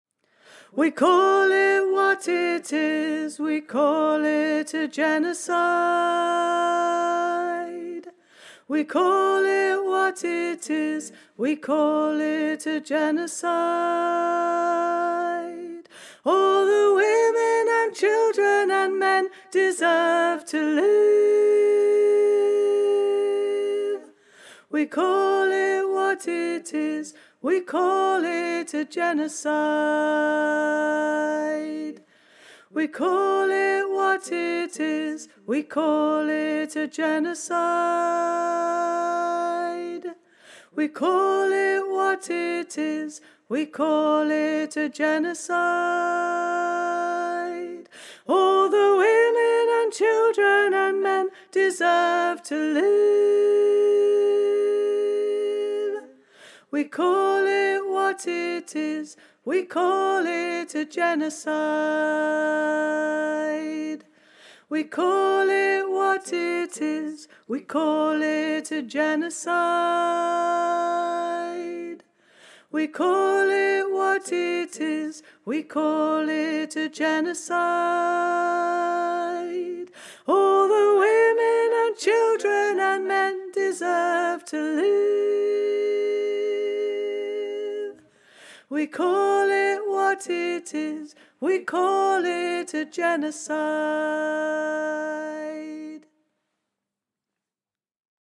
A street song
High (tune):